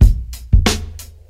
• 92 Bpm Breakbeat B Key.wav
Free drum groove - kick tuned to the B note. Loudest frequency: 800Hz
92-bpm-breakbeat-b-key-jLc.wav